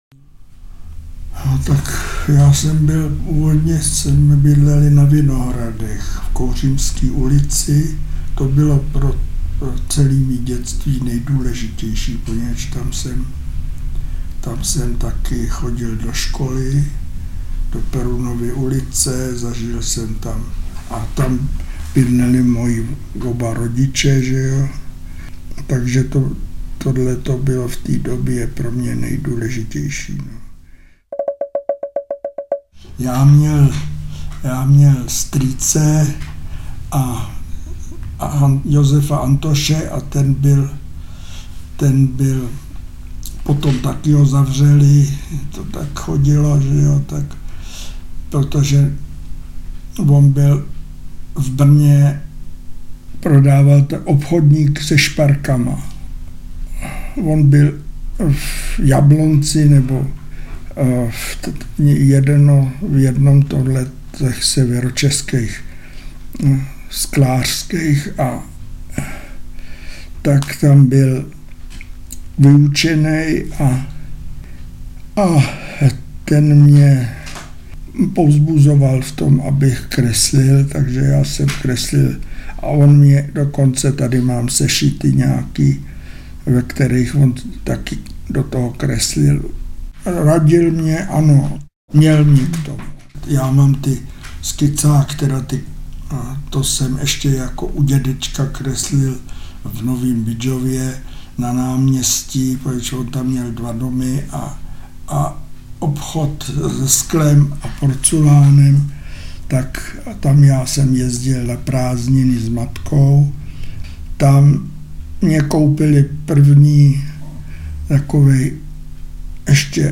K této příležitosti jsme pro vás přichystali k poslechu jeho autentické vyprávění o životě a připojili i ukázky z jeho tvorby básnické, které sám interpretuje.
Audio knihaJiří Šalamoun ... a ještě tohle!
Další básně v podání Jany Trojanové a Václava Knopa doplňují moudrý pohled na svět tohoto světoznámého grafika.
• InterpretJiří Šalamoun